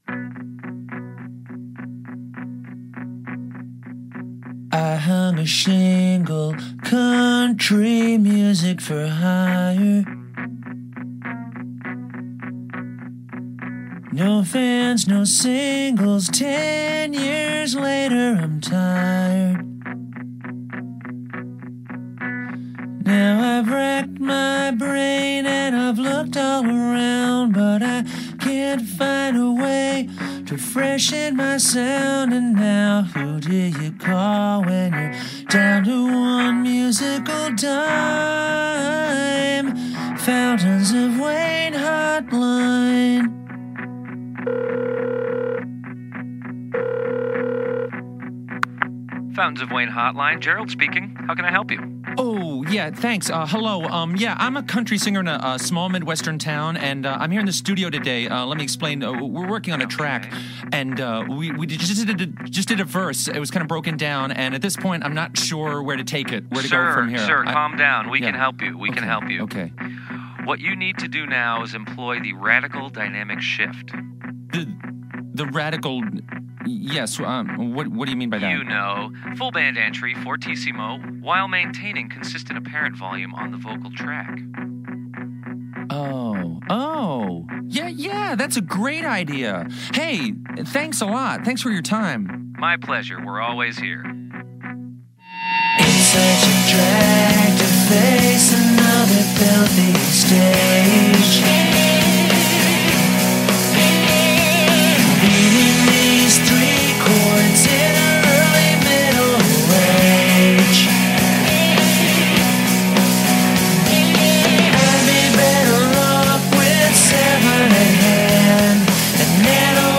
power poppers
Great pop tune